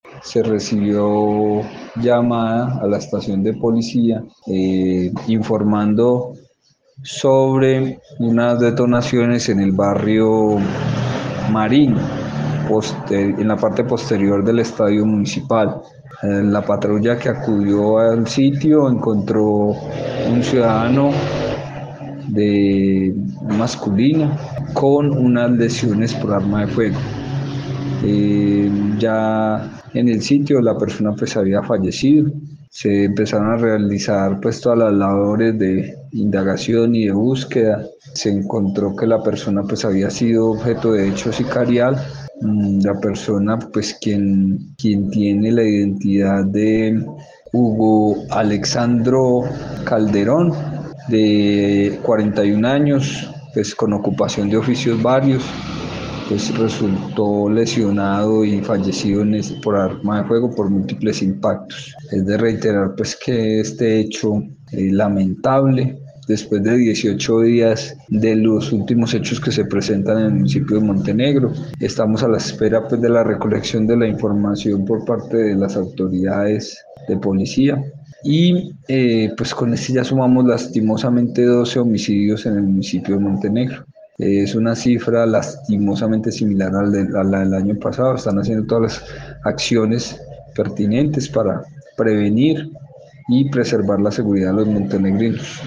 Secretario de Gobierno de Montenegro